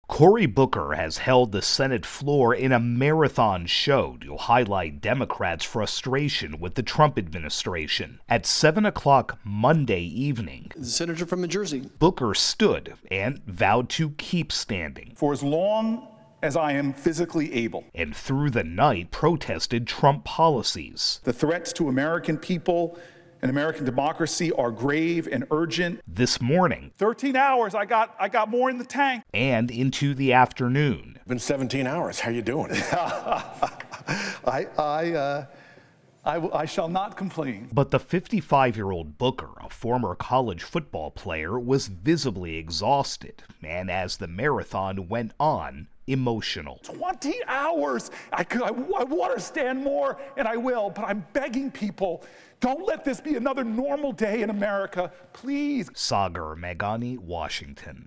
reports on Senator Cory Booker's marathon floor speech.